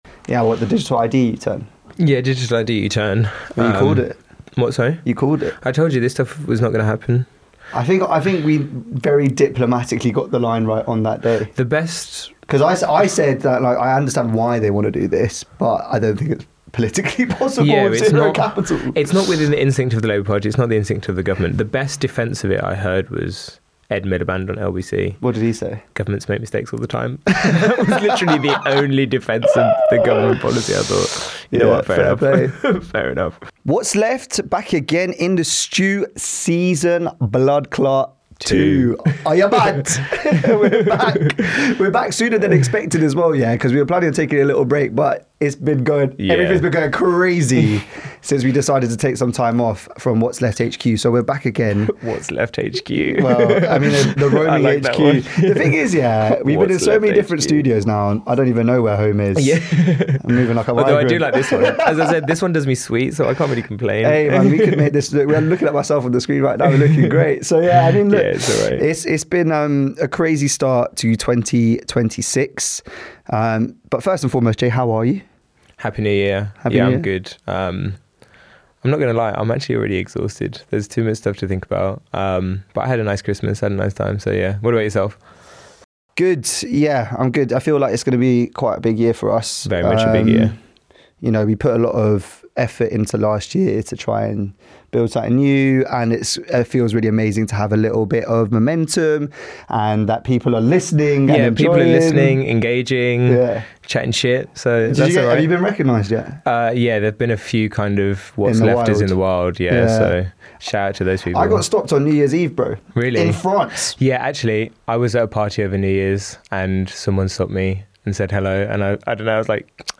So here we are with a rapid 30 min catch up on Trump dictating an emerging multipolar world order and a 1hr interview